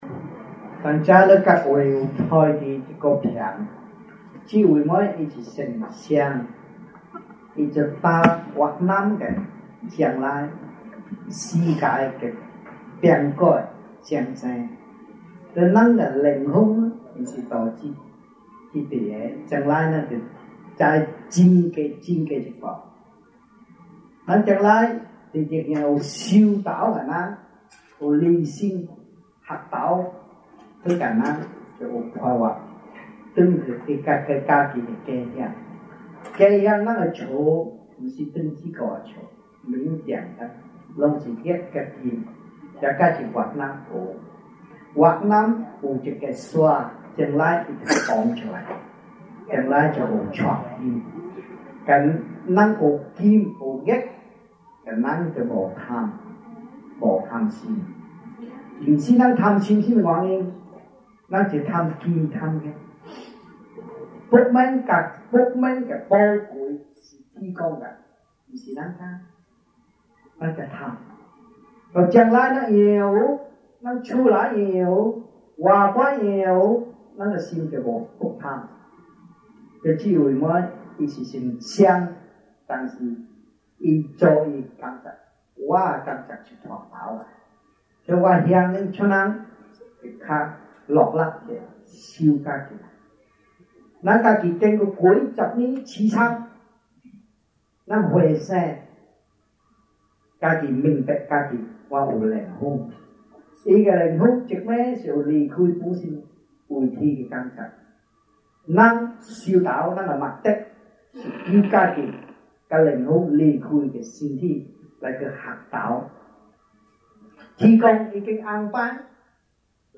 Lectures-Chinese-1982 (中文講座)